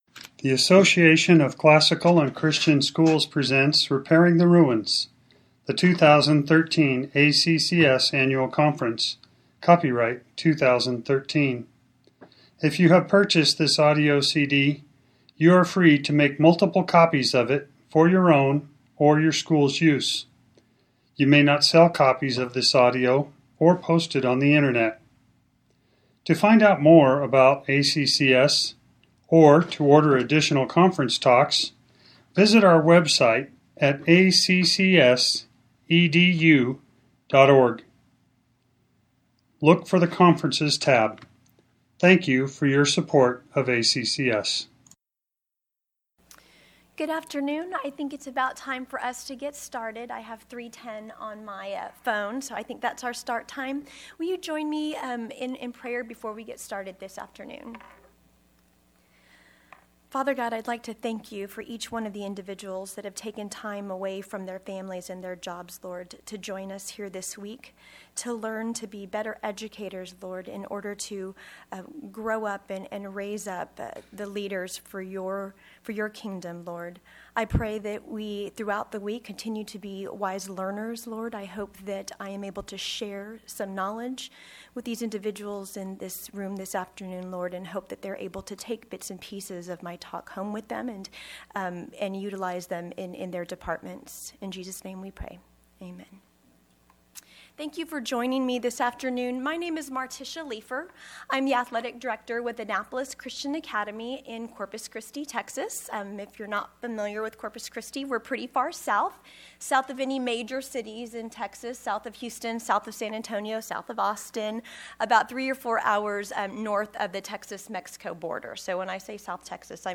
2013 Workshop Talk | 1:05:01 | All Grade Levels
The Association of Classical & Christian Schools presents Repairing the Ruins, the ACCS annual conference, copyright ACCS.